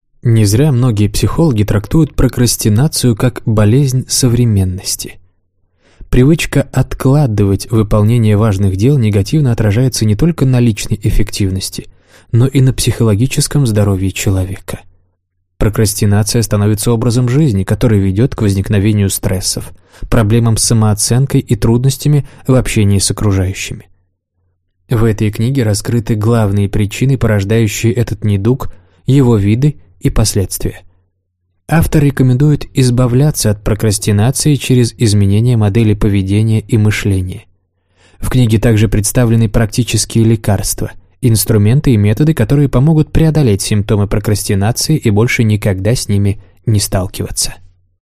Аудиокнига Сейчас или никогда! Как перестать откладывать дела | Библиотека аудиокниг